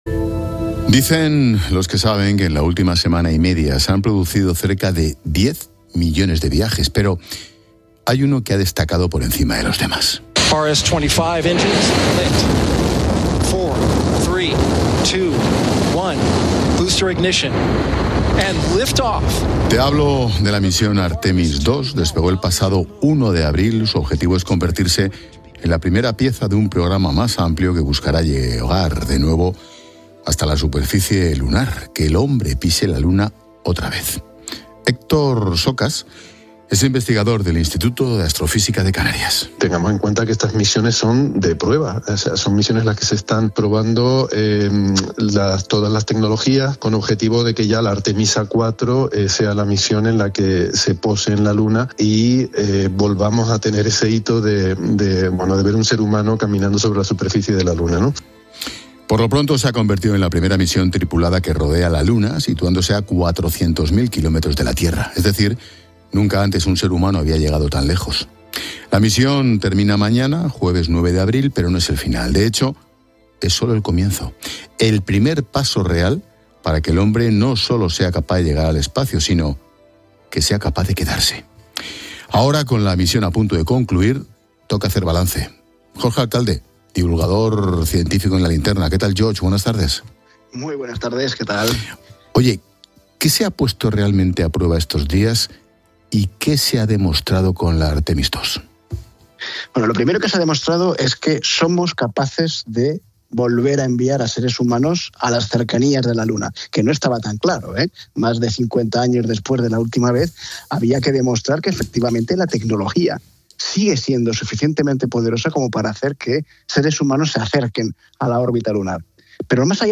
En el programa 'La Linterna' de COPE, el divulgador científico Jorge Alcalde ha analizado con Ángel Expósito las claves de una misión que, aunque no ha implicado un alunizaje, es “muy importante” y supone “el primer paso real para que el hombre no solo sea capaz de llegar al espacio, sino que sea capaz de quedarse”.